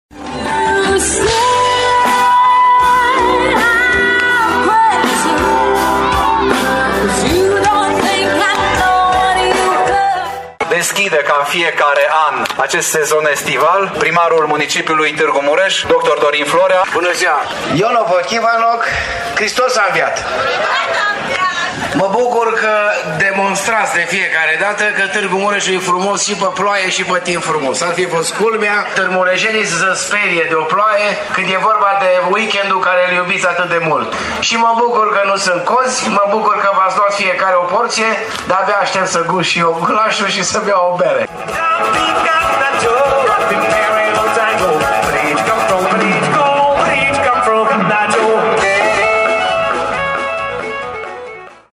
Primarul Municipiului Târgu-Mureș, Dorin Florea: